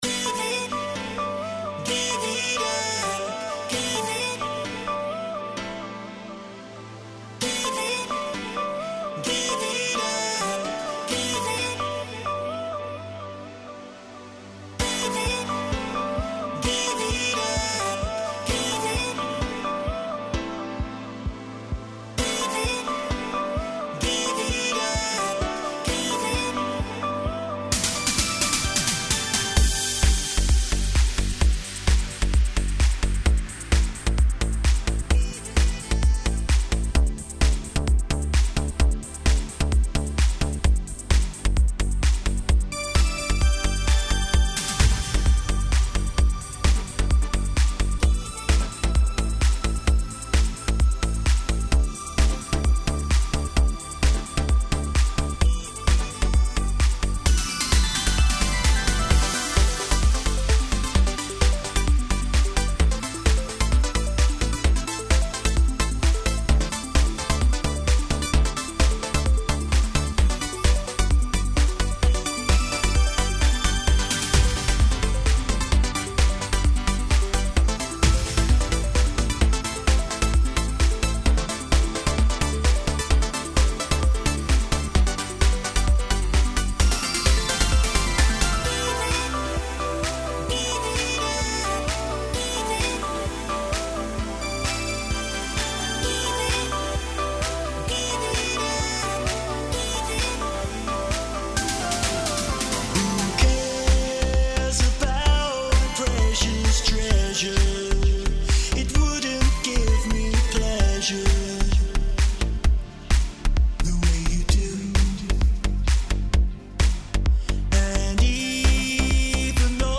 • Extended Mix – delivering the full-length club experience